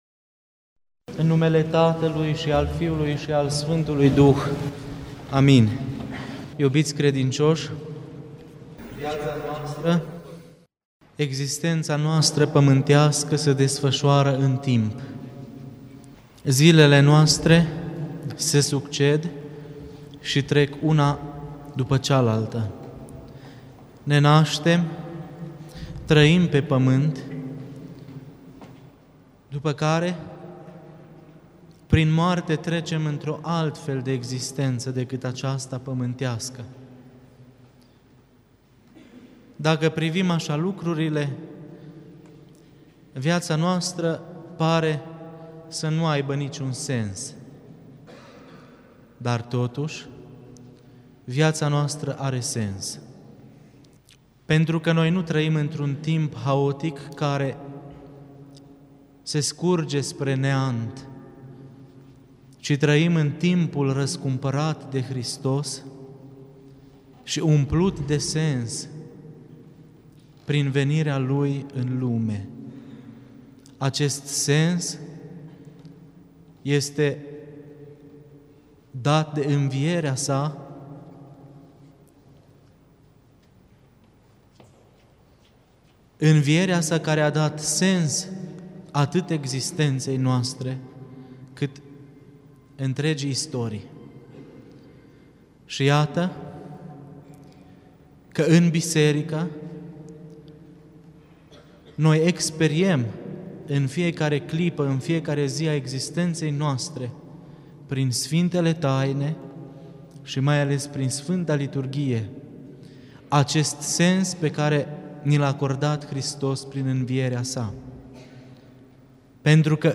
Cuvinte de învățătură Predică la Duminica a 33-a după Rusalii